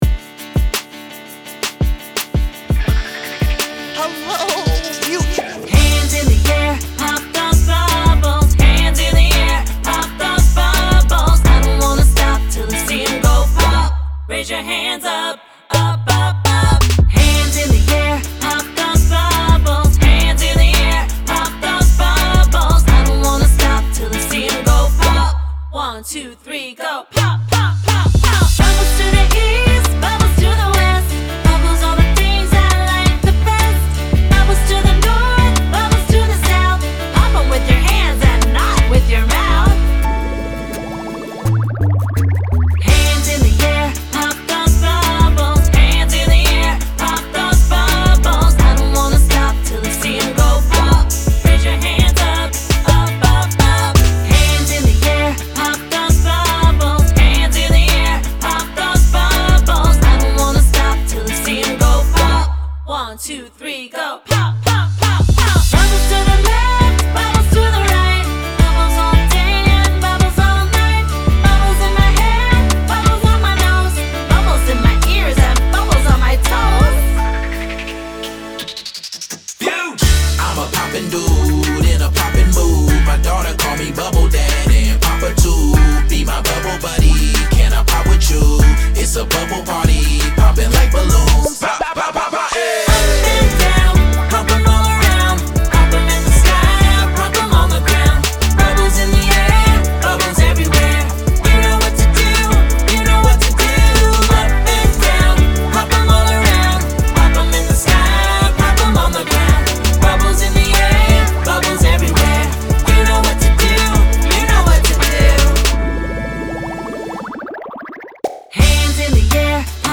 Children's